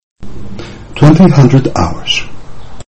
20:00 προφέρεται twenty hundred hours.